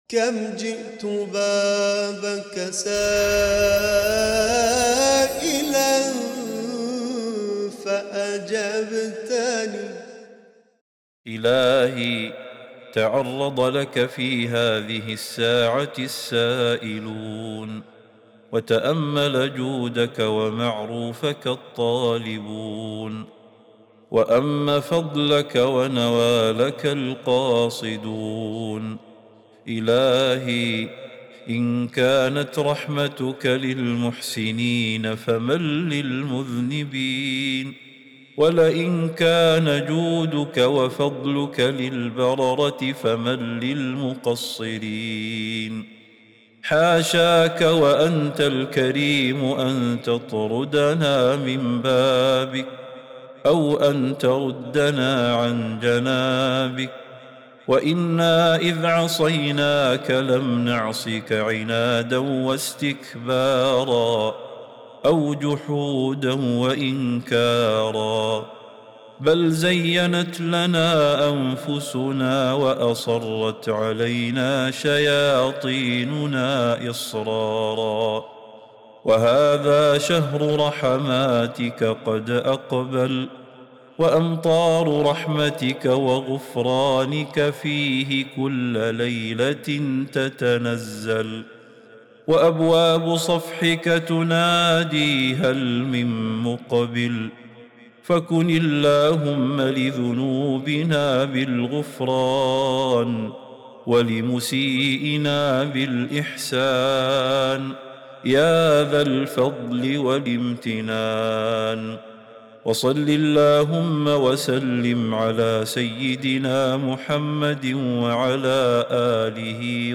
دعاء خاشع يلجأ فيه العبد إلى رحمة الله وجوده، معترفاً بتقصيره ومتوسلاً بمغفرة ربه. النص يذكر فضائل شهر الرحمة ويستحضر نزول الرحمات فيه، مما يجعله مناسباً لأوقات الدعاء والمناجاة.